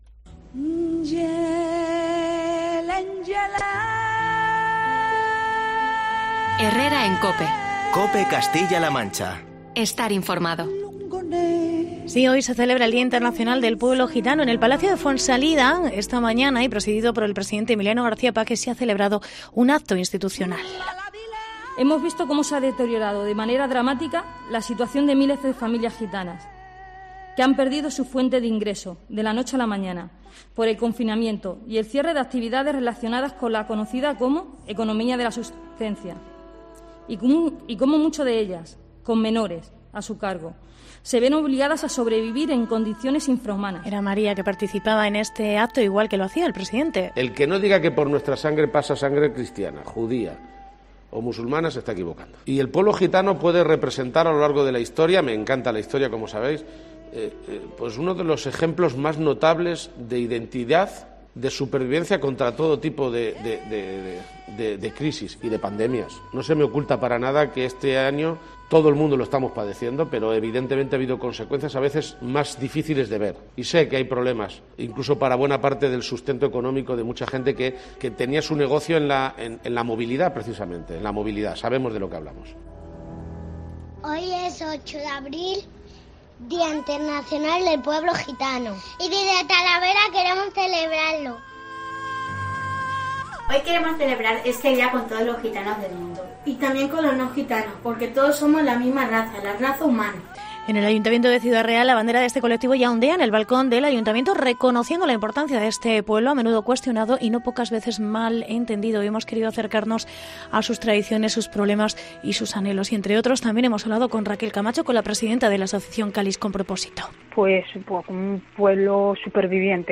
Reportaje pueblo gitano